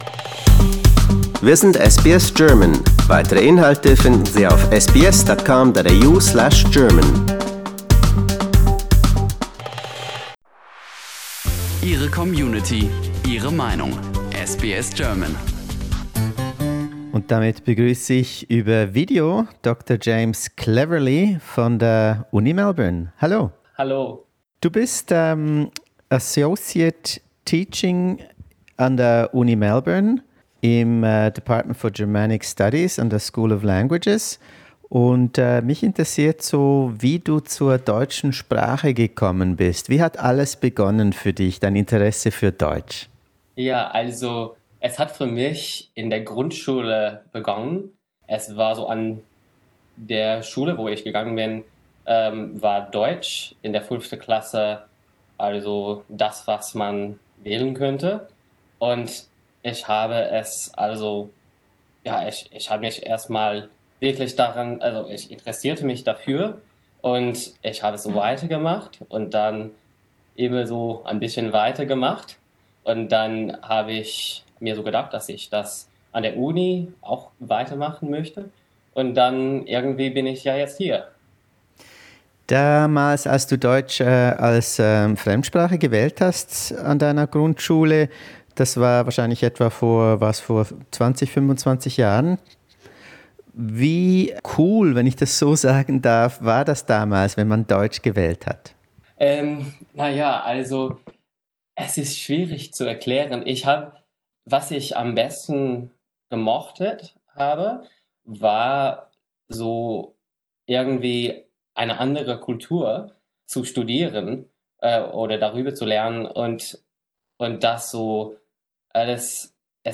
In unserer Lockdown-Serie sprechen wir per Video mit einem Germanisten von der Uni Melbourne.